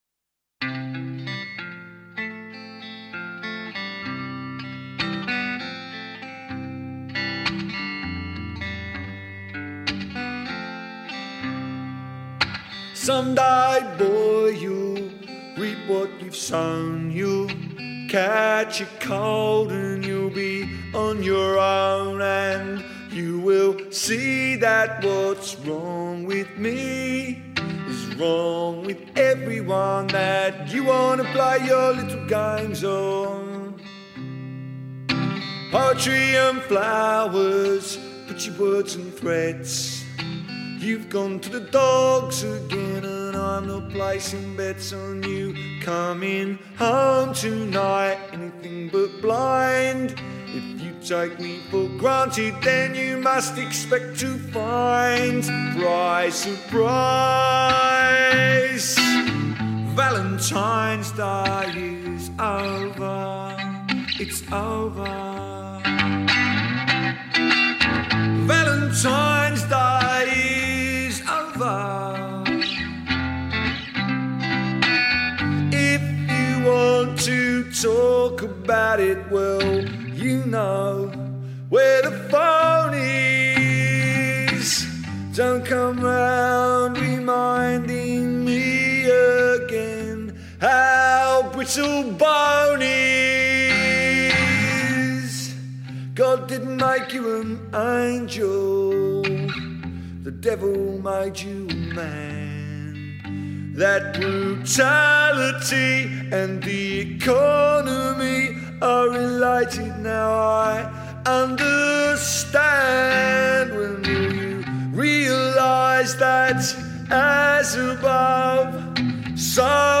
Peel Session version